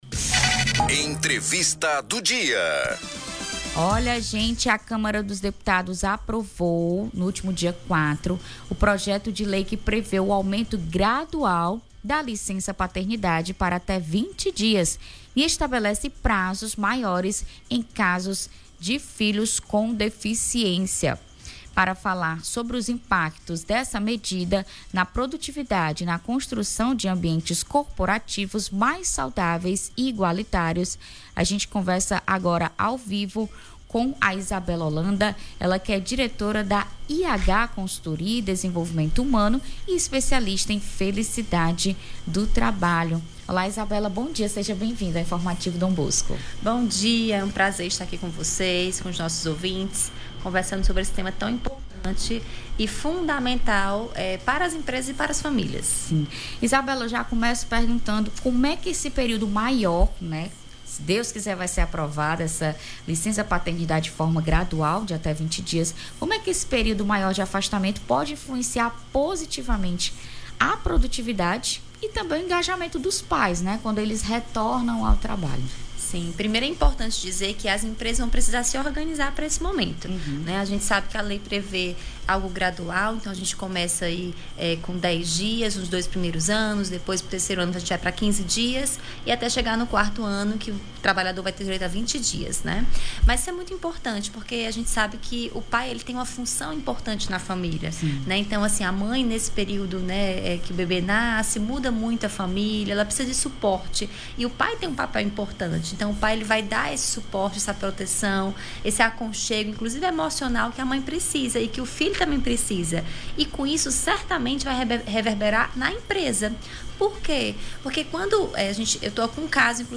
Entrevista do dia